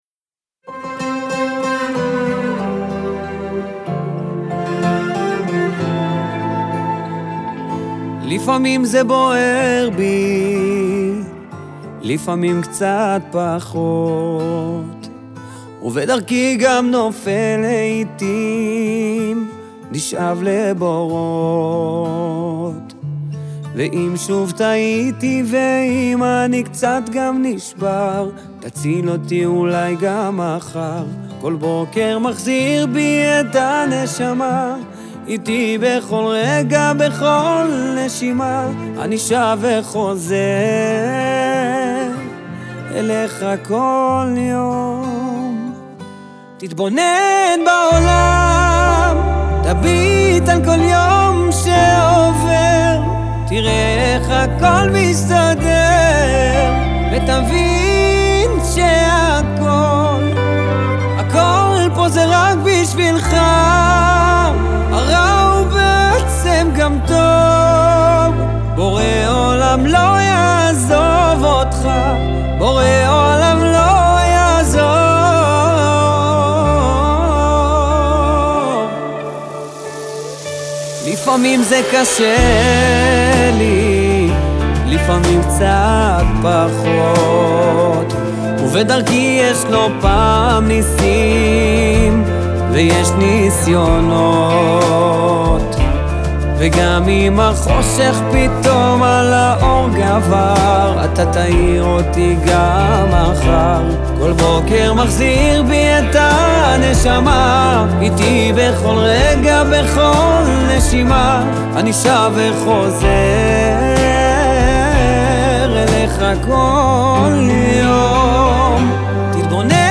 ביצוע נוסף בז'אנר הים תיכוני
יכולותיו הווקאליות המרהיבות והמגוונות